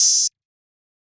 OH - Krazy Open Hat.wav